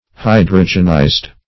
Search Result for " hydrogenized" : The Collaborative International Dictionary of English v.0.48: Hydrogenize \Hy"dro*gen*ize\, v. t. [imp.
hydrogenized.mp3